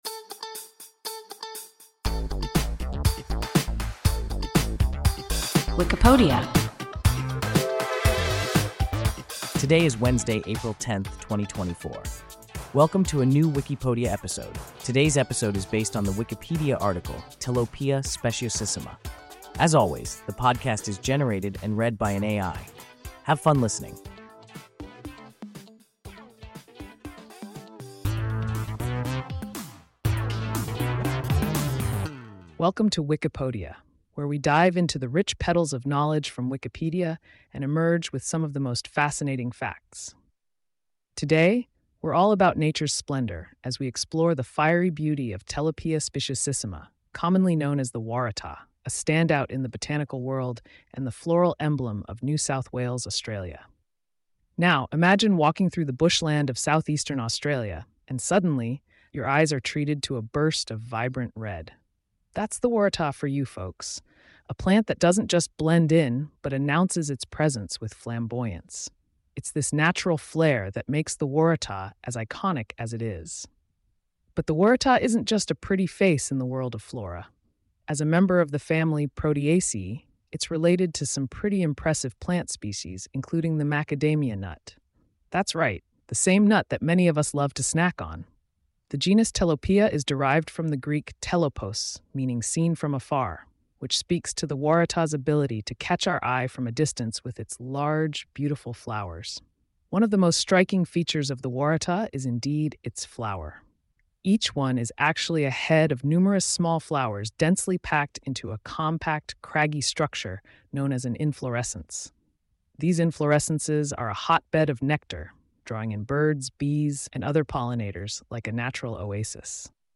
Telopea speciosissima – WIKIPODIA – ein KI Podcast